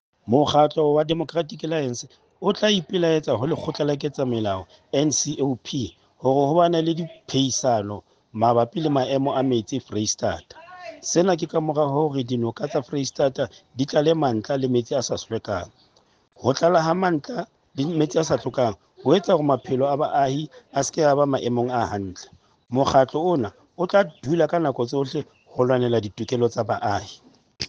Sesotho soundbite by Cllr Stone Makhema.